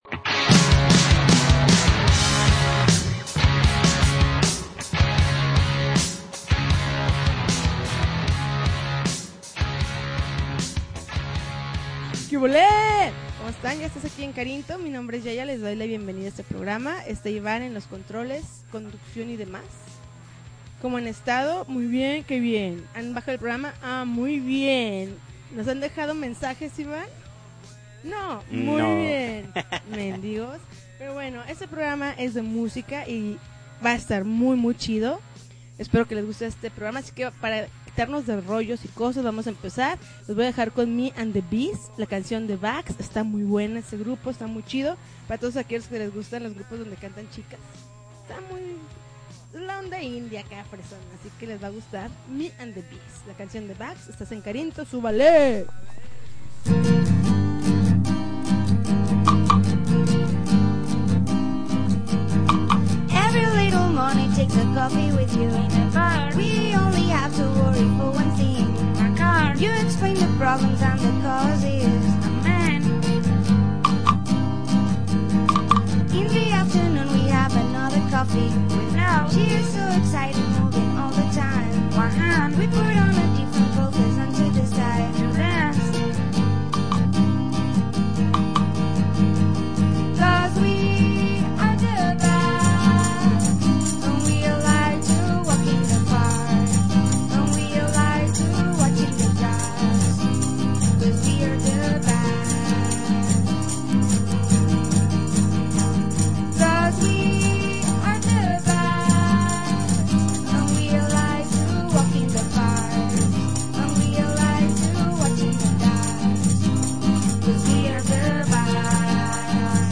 August 14, 2011Podcast, Punk Rock Alternativo